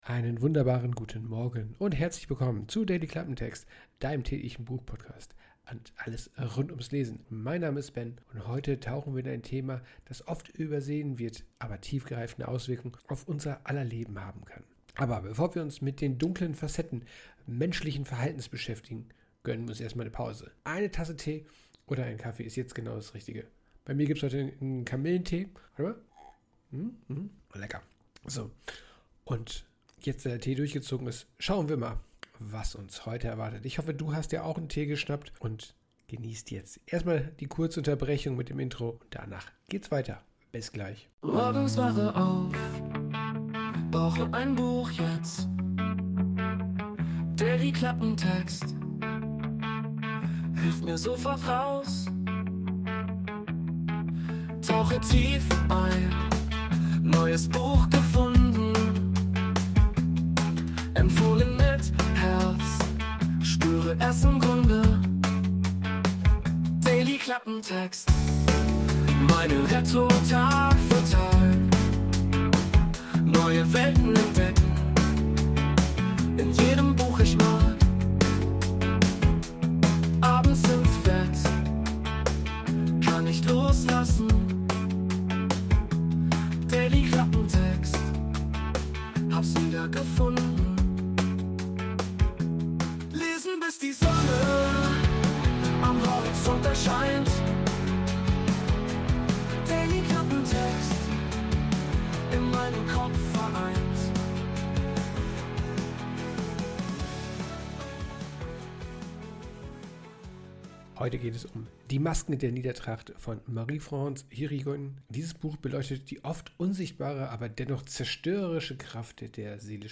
Peaceful Cinematic (No Copyright Music)
Intromusik: Wurde mit der KI Suno erstellt.